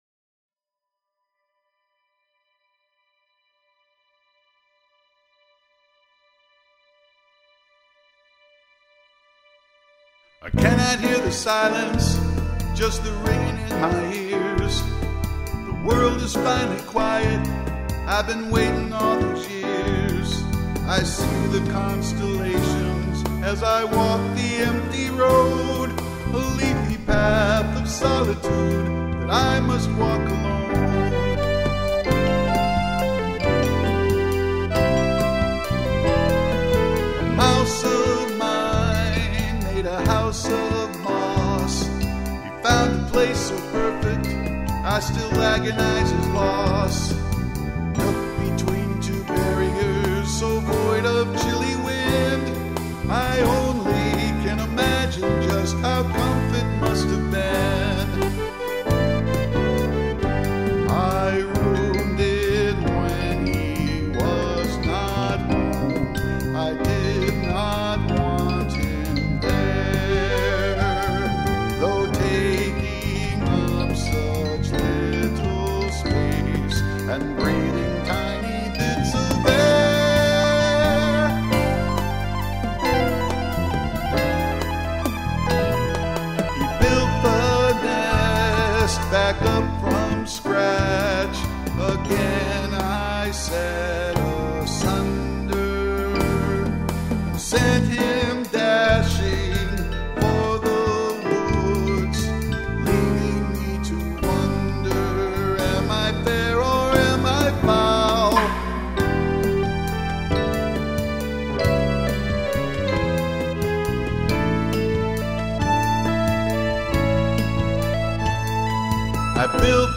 Trumpet
Bass